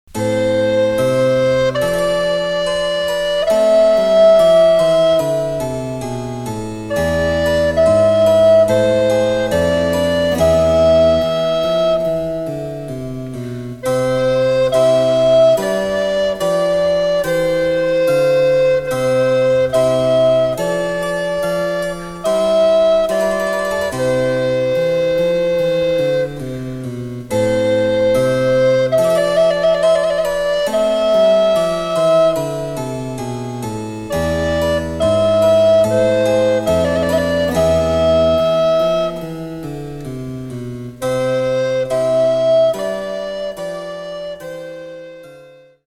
デジタルサンプリング音源使用
・伴奏はモダンピッチのみ。